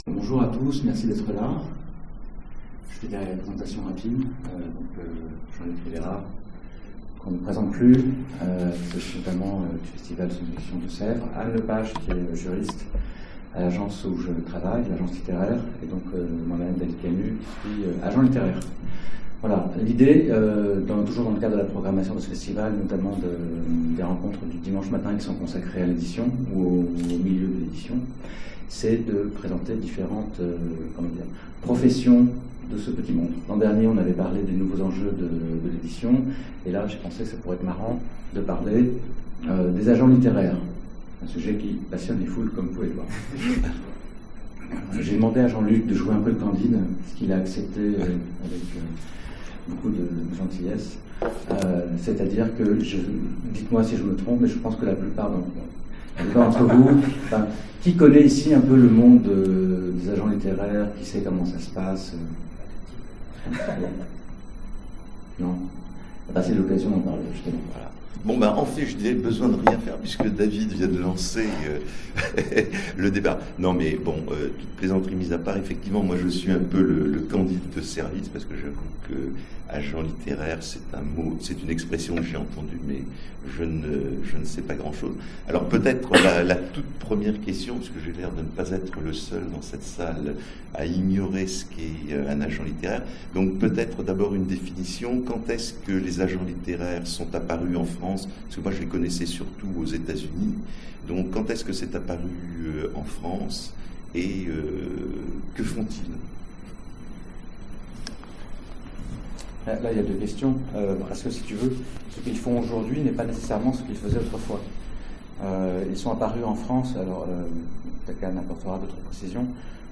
Conférence FMI 2017 : Les ateliers de l'édition, le métier d'agent